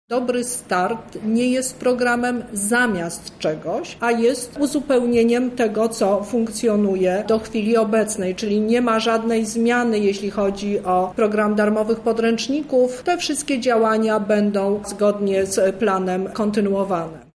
Co więcej, nie koliduje z innymi świadczeniami, o czym przekonuje Teresa Misiuk, Lubelska Kurator Oświaty: